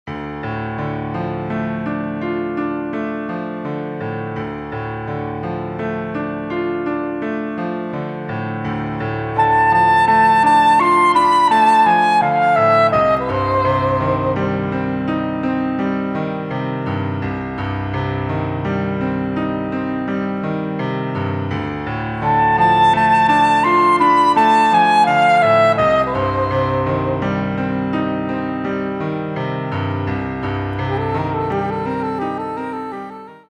Saxophone en Sib et Piano